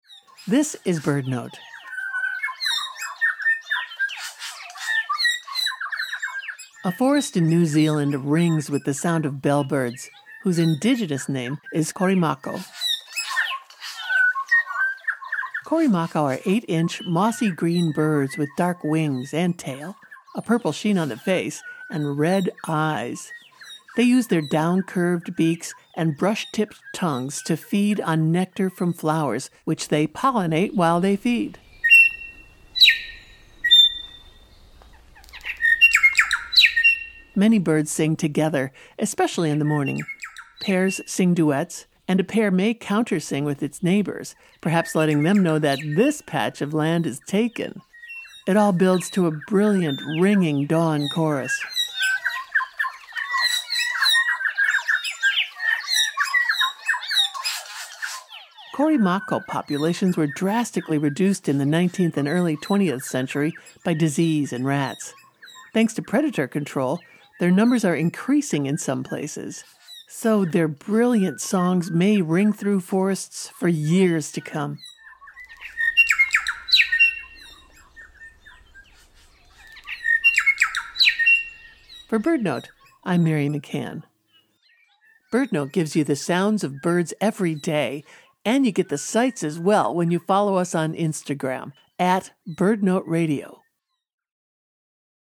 A forest in New Zealand rings with the sound of bellbirds, also known as Korimako or Makomako. Many bellbirds sing together, especially in the morning. Pairs sing duets.
It all builds to a brilliant, ringing dawn chorus.